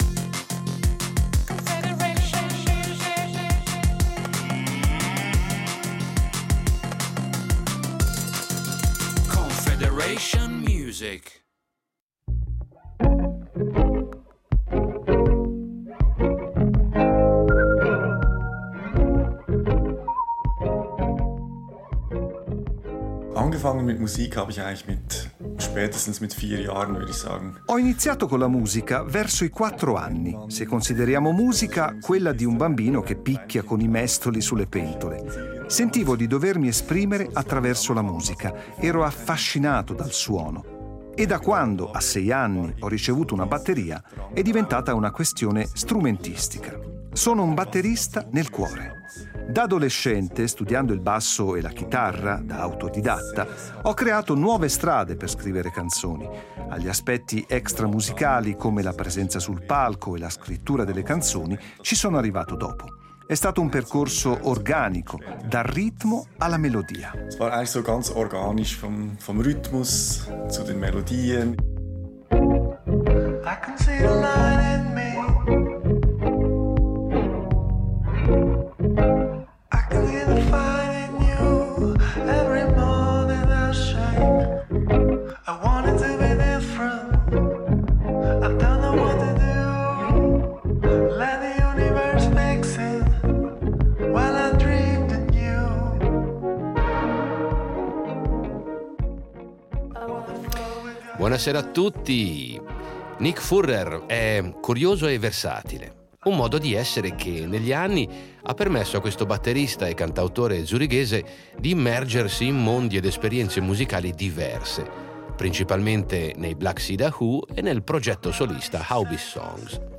Musica pop